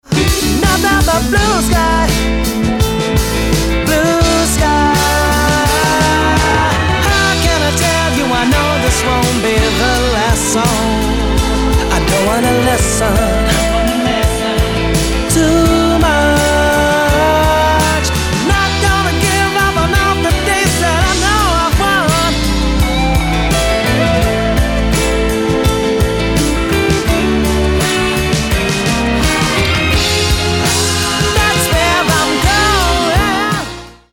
Britská jazz-funková skupina